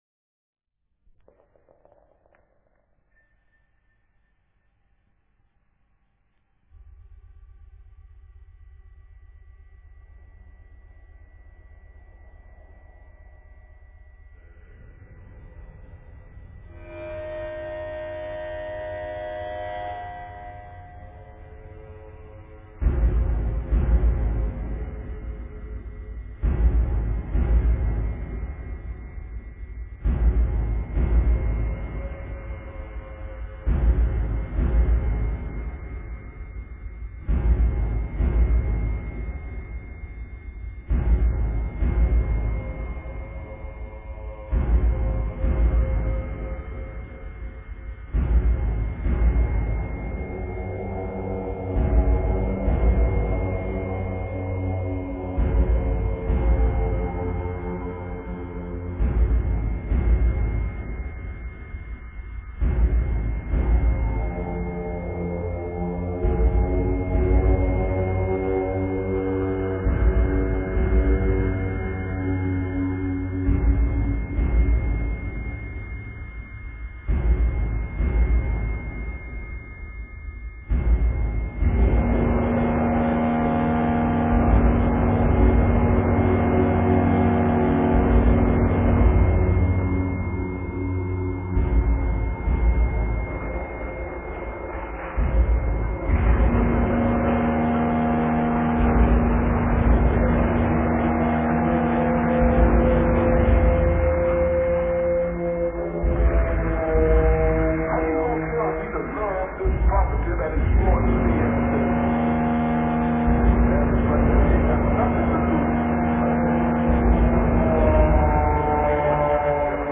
Назад в Dark Ambient & Death Industrial